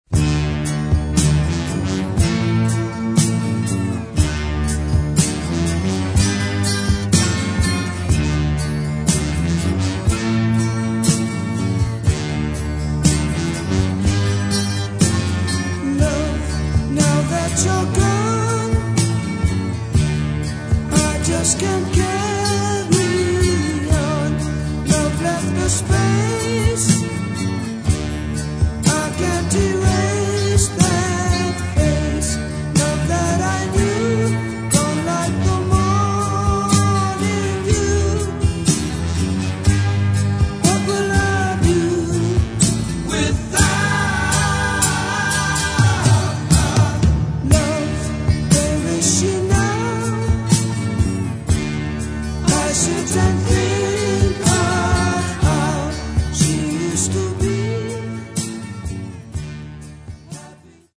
Рок